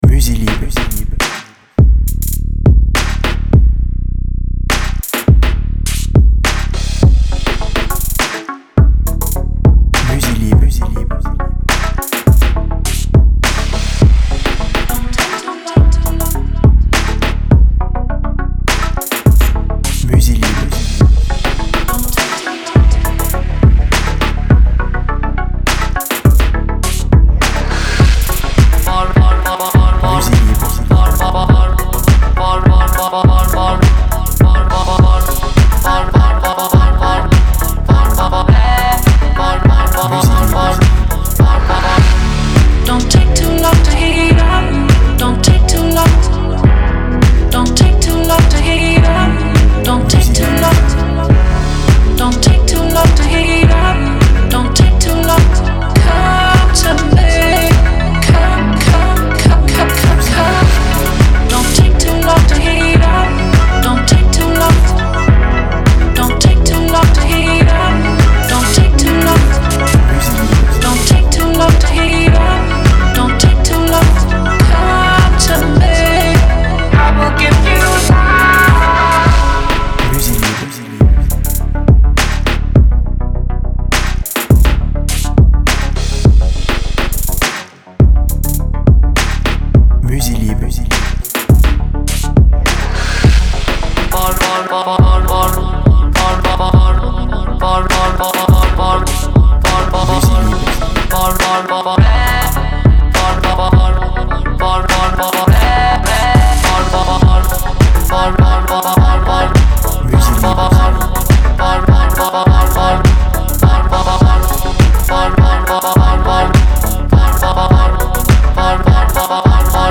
BPM Lent